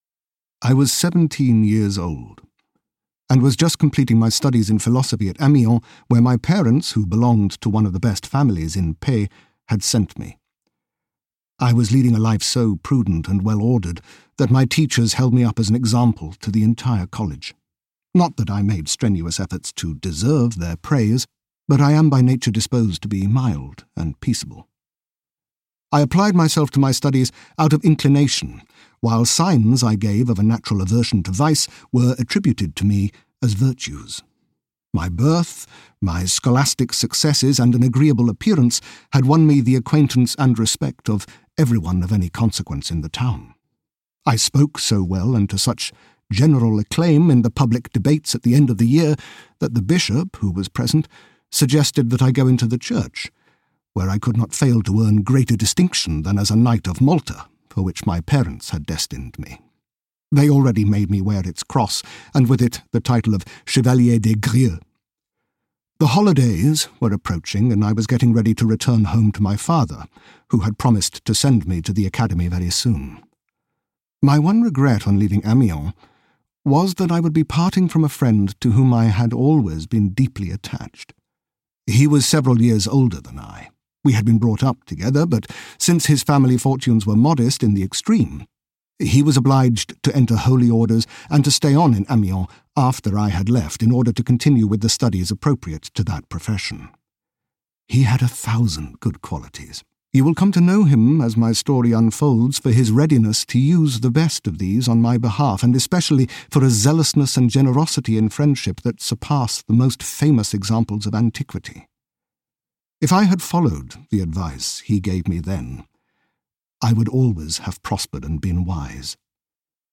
Manon Lescaut audiokniha
Audiobook Manon Lescaut, written by Abbé Prévost.
Ukázka z knihy